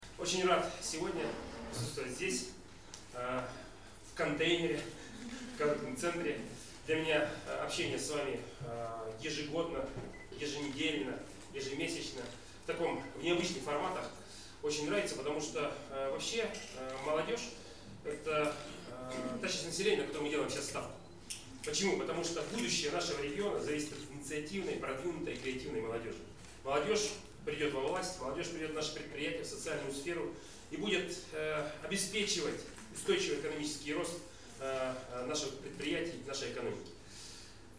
Встреча была посвящена празднованию Дня российской молодежи. Мероприятие состоялось в среду, 26 июня, в коворкинг-офисе «Контейнер».
Олег Кувшинников рассказывает о молодежных проектах